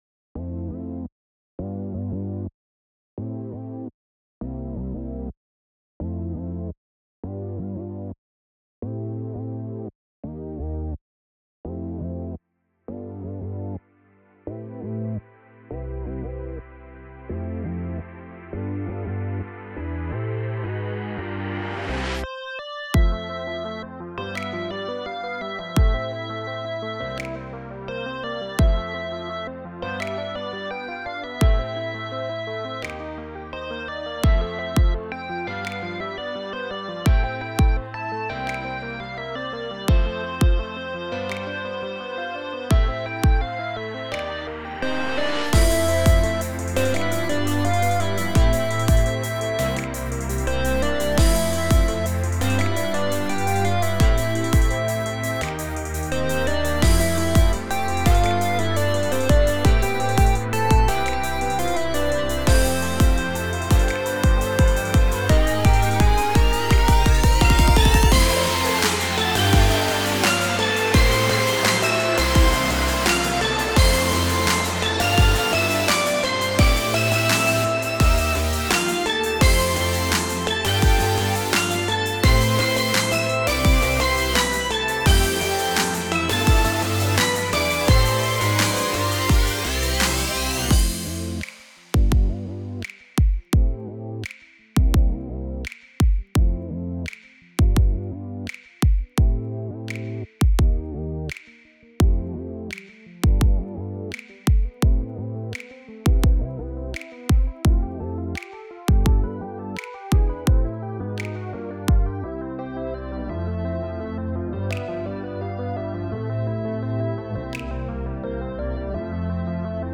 BGM
夜と朝の狭間に広がる静けさと、懐かしさを感じを描いた幻想的なBGM。